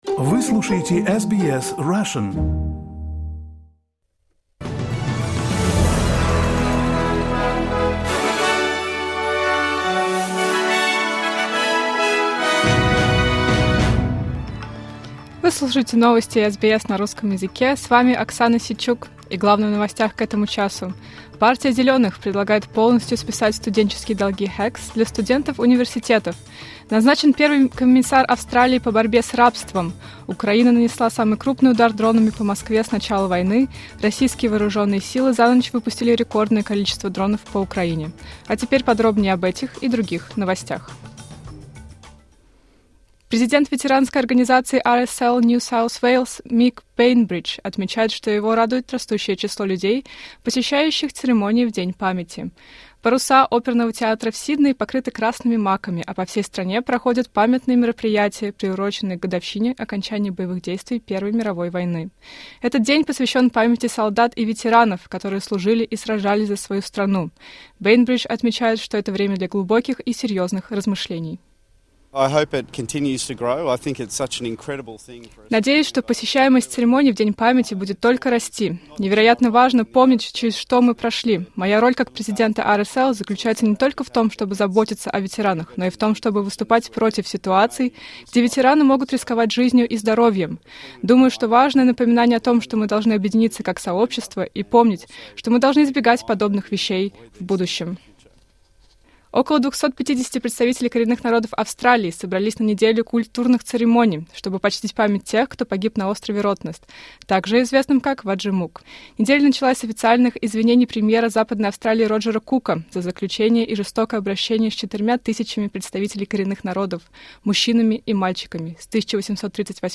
Новости SBS на русском языке — 11.11.2024